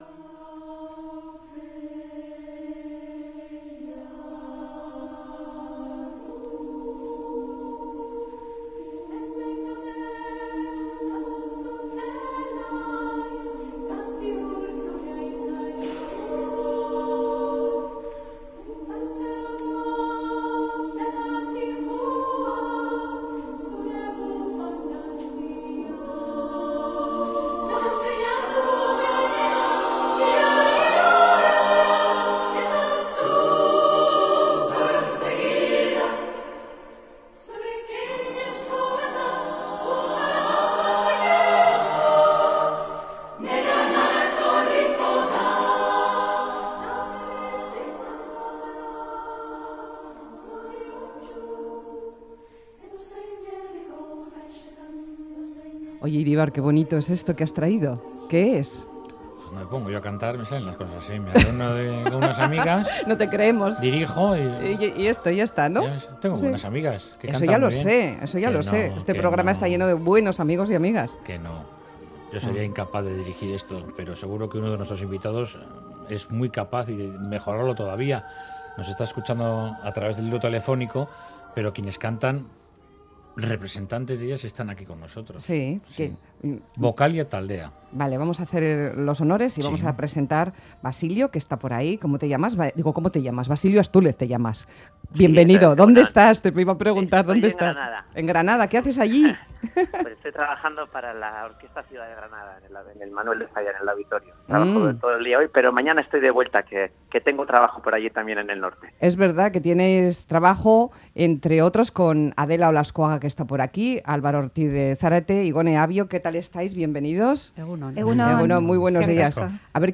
VOCALIA TALDEA: un coro de mujeres que cantan obras escritas por mujeres
Audio: Vocalia Taldea es un coro femenino con 20 años de trayectoria, que integra a 34 cantantes de Hegoalde.